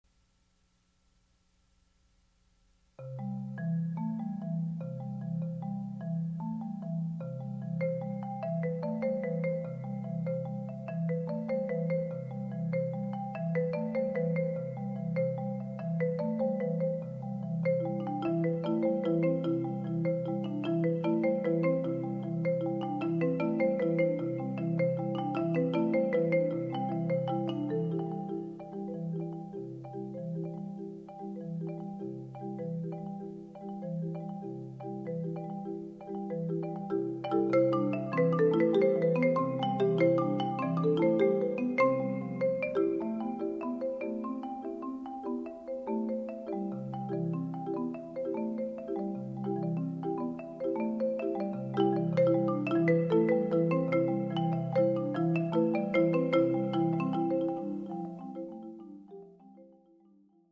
Mallet/Marimba Trios